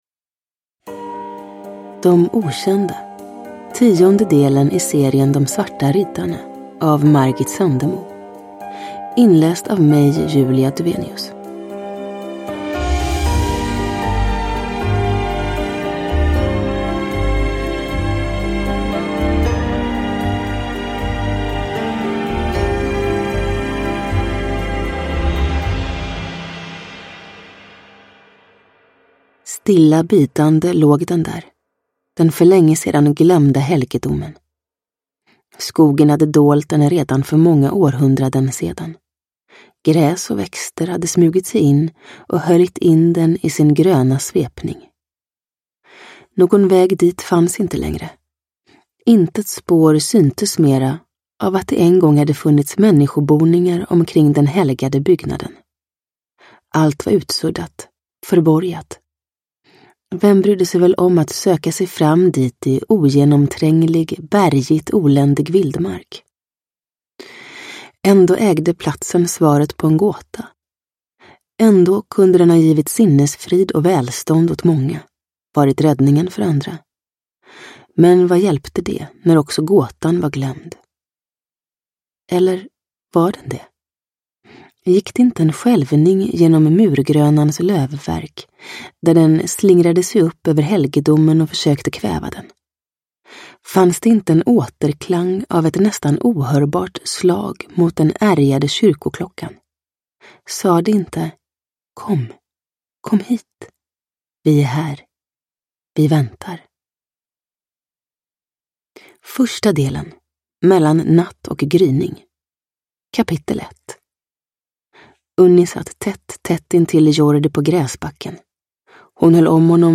De okända – Ljudbok – Laddas ner
Uppläsare: Julia Dufvenius